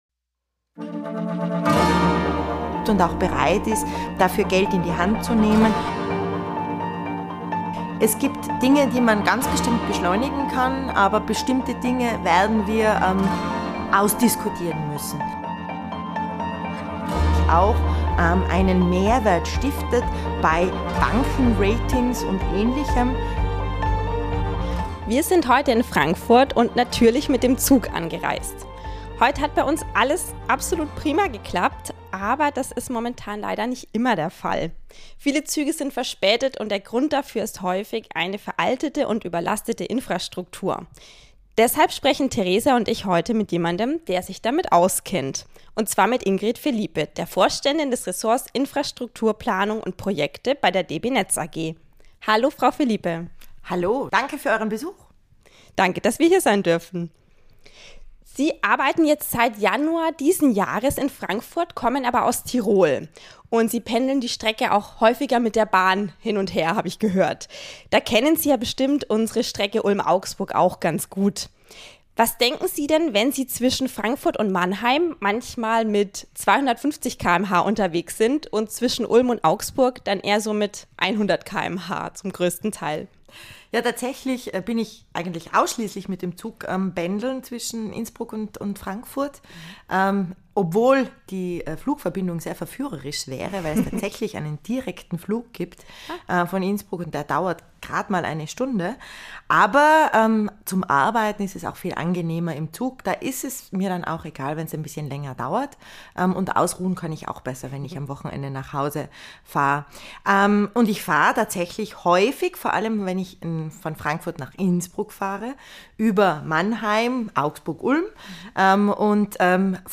Ein Gespräch darüber, was in Österreich besser läuft als in Deutschland, über den ältesten Elektromobilitäts- und Ridesharing-Anbieter der Welt und über Themen, die ausdiskutiert werden müssen.